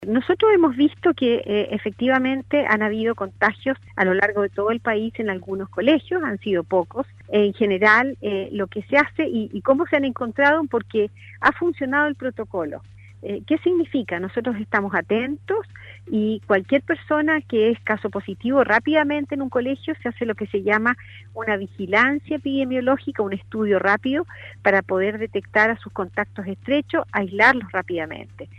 En conversación con Radio Sago, la Subsecretaria de Salud Pública, Paula Daza se refirió a el Plan de Búsqueda Activa de Covid-19 en inmediaciones de Establecimientos educacionales.